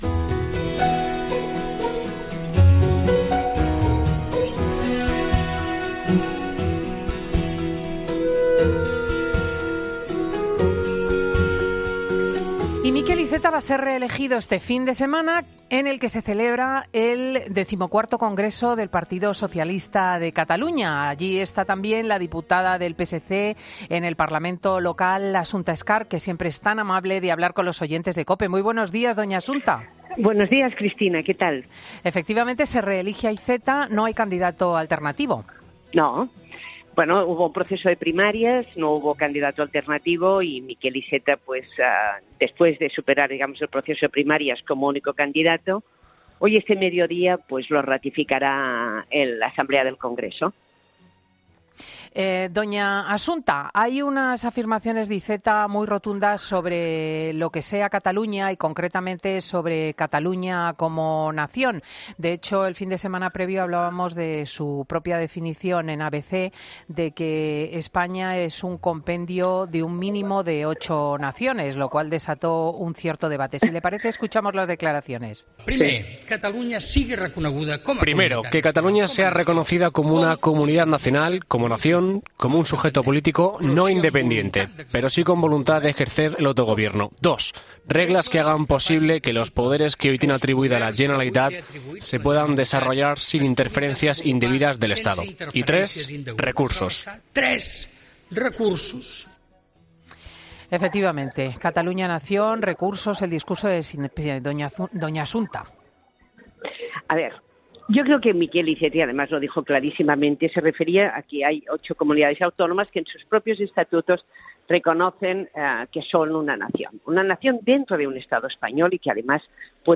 La diputada del PSC, Asunta Scarp en 'Fin de Semana' de la Cadena COPE